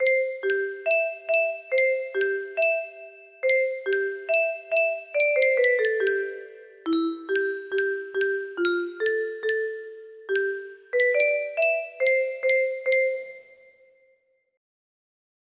Детска песничка